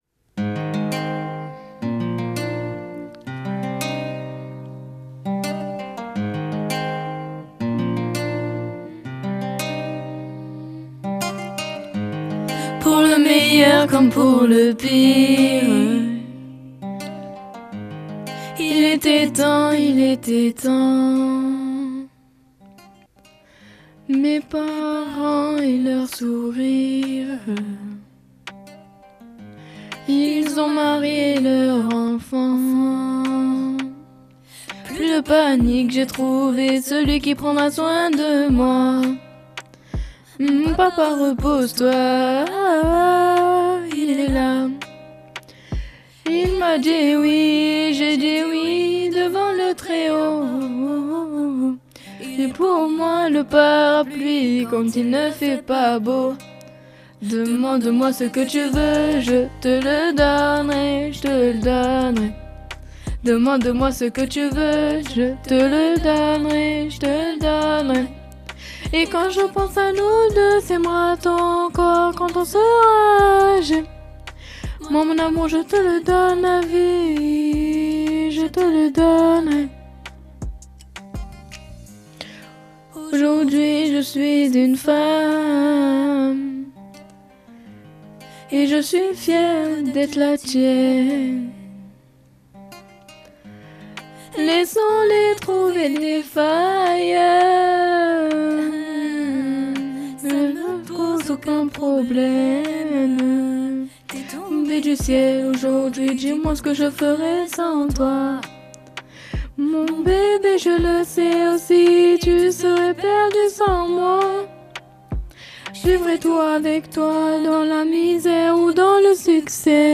très douce interprétation